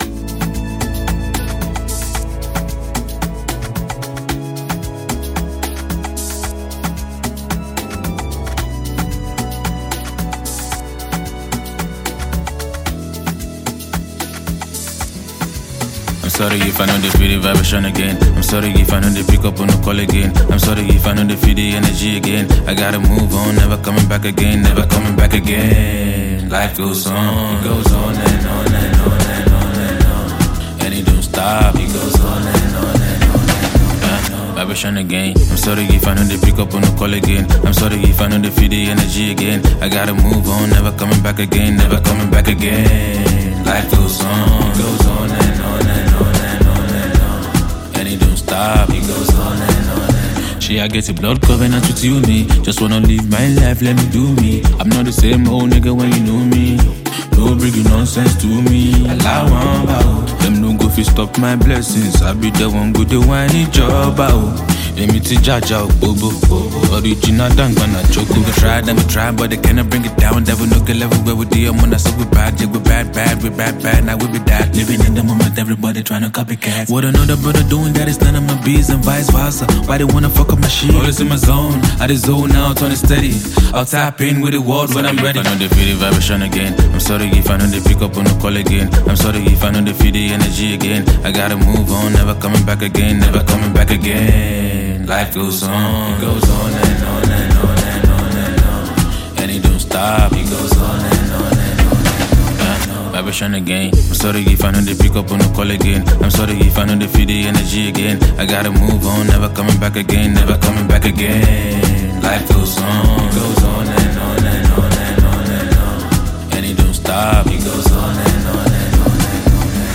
The album guarantees exceptional vocal delivery.